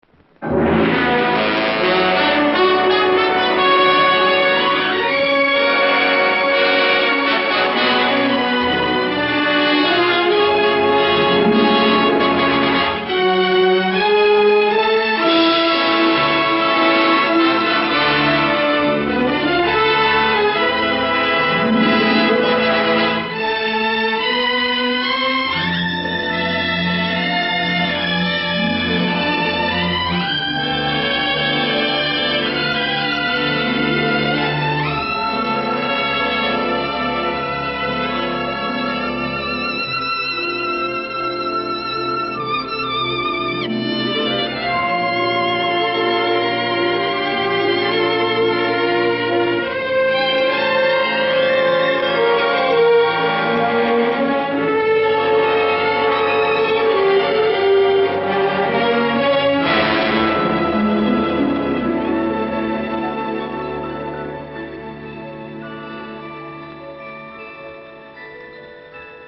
no-man-of-her-own-1950-opening-sequence-audiotrimmer-com.mp3